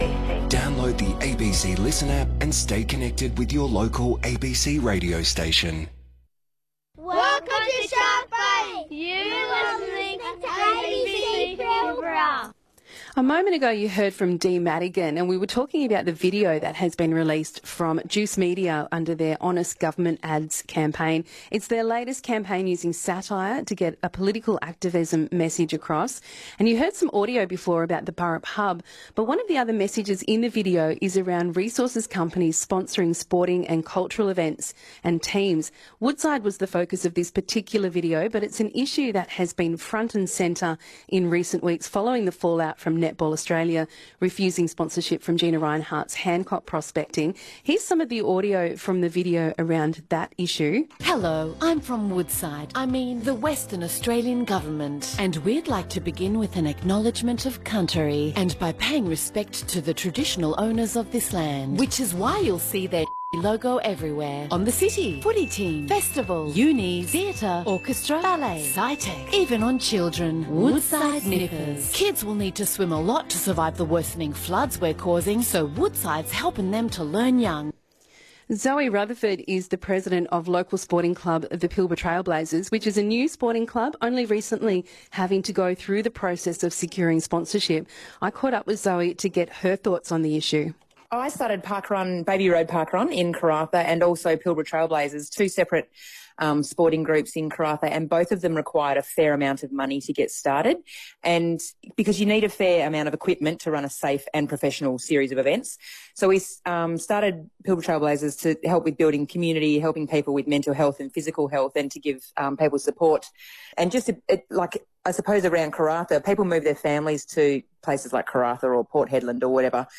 Discussion on sponsorships controversy